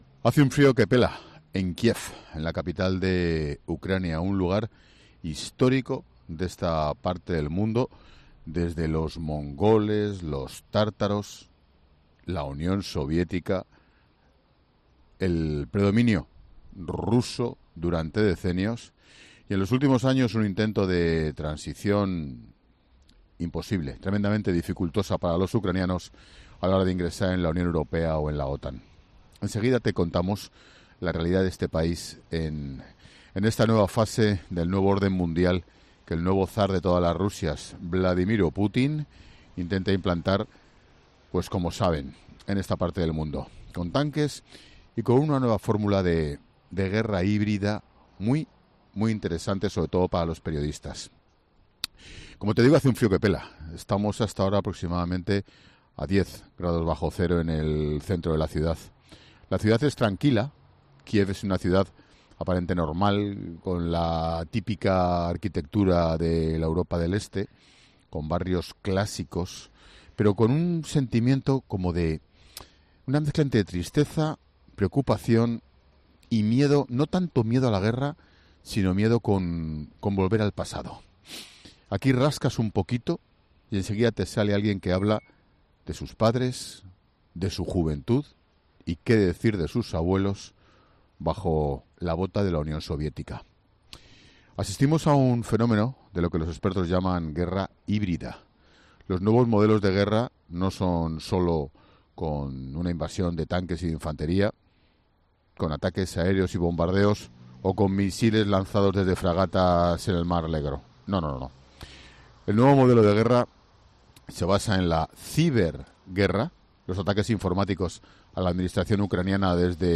Monólogo de Expósito
El director de 'La Linterna' enciende el programa desde Kiev, desde donde seguirá de cerca la evolución de la tensión entre Rusia y Ucrania